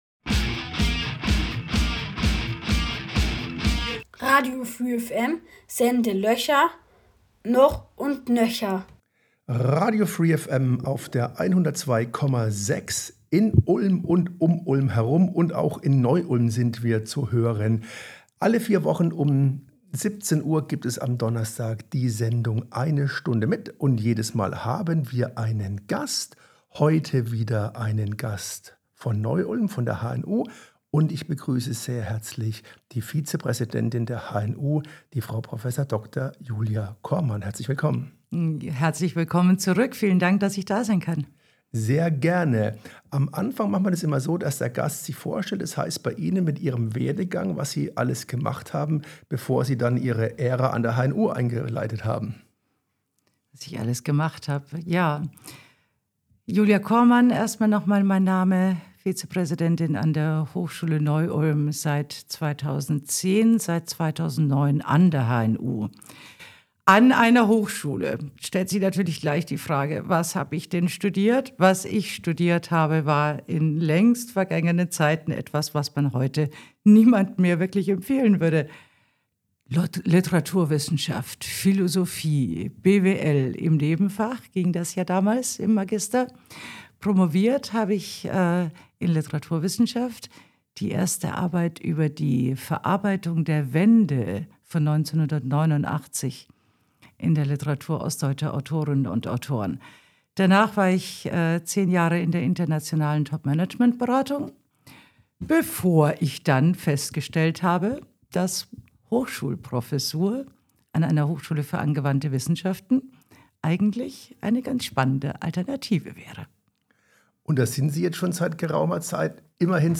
Wir führen ein philosophisches Gespräch über die "Faszination Wildnis". Was ist Wildnis? Welche wissenschaftlichen und lebensweltlichen Naturauffassungen gibt es?